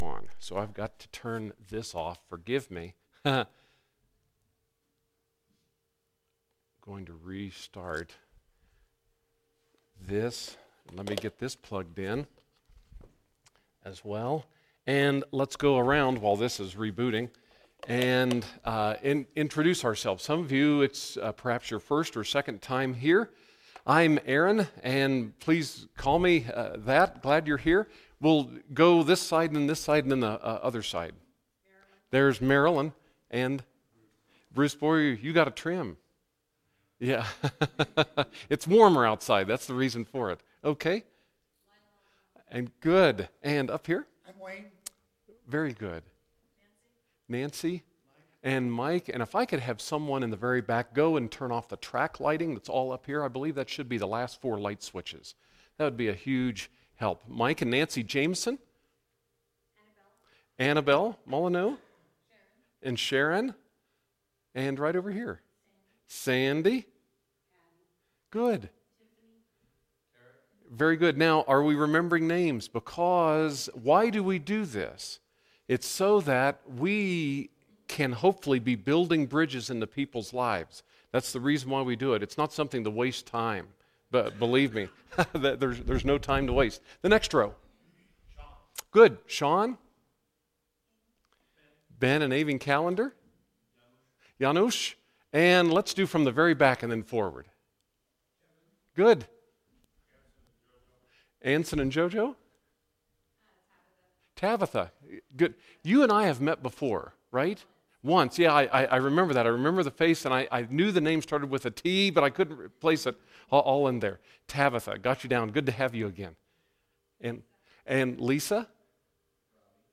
Sunday School…